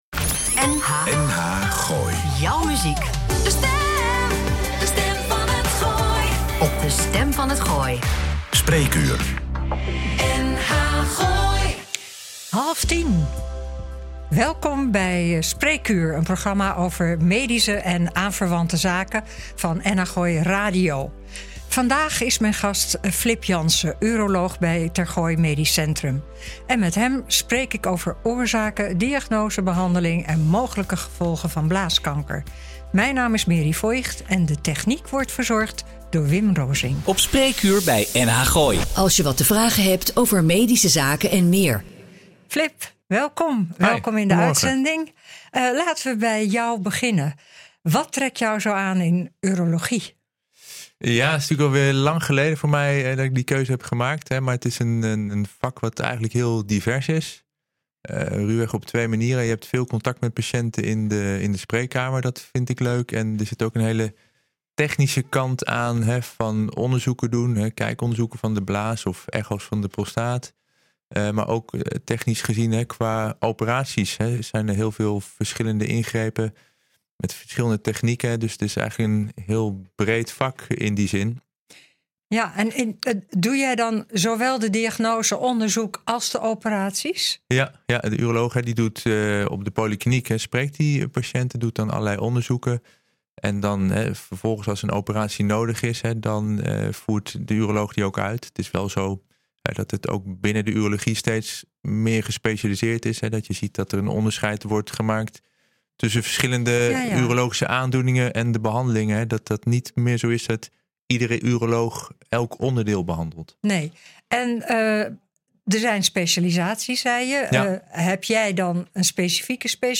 Een gesprek